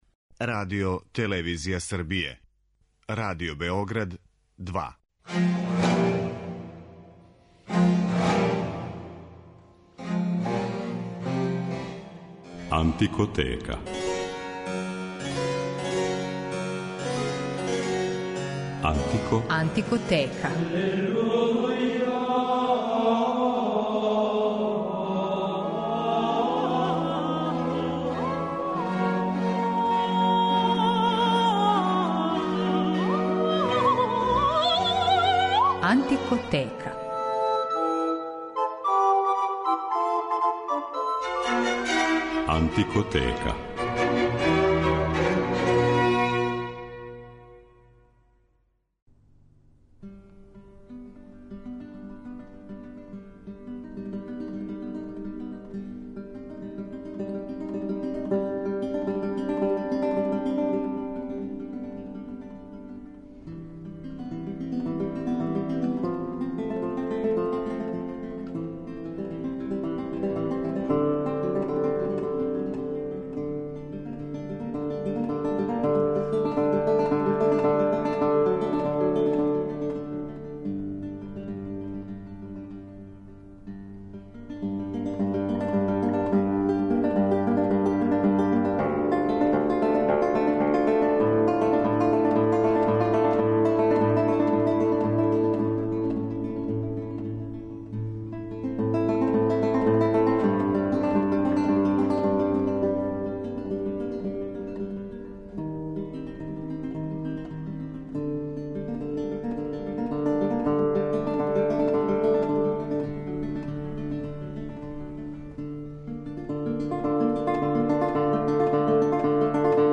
Једном од најоригиналнијих анcамбала у свету ране музике - L`Arpeggiata и његовом оснивачу и руководиоцу Кристини Плухар, посвећена је данашња емисија, у којој ћете моћи да чујете како ови врхунски уметници на маштовит и свеобухватан начин оживаљавају барокну епоху. Они изводе компоновану музику, али и народне песме и игре првенствено 17. века.